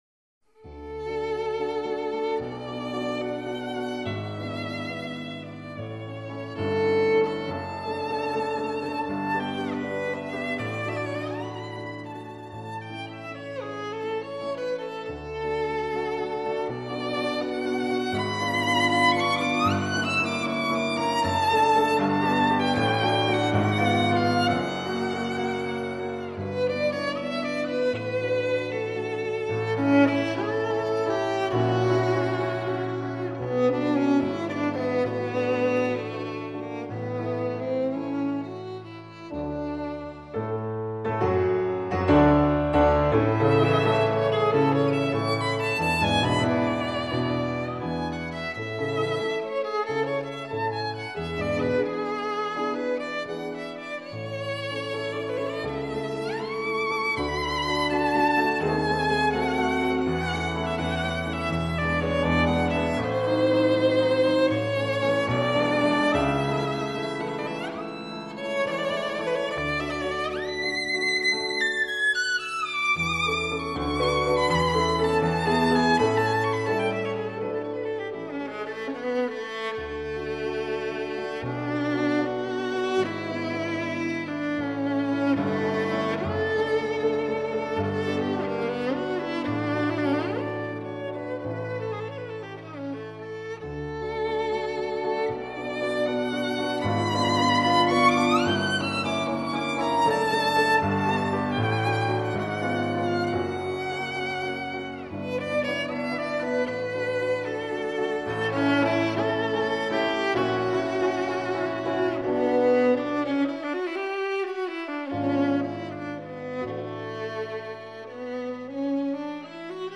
piano1.mp3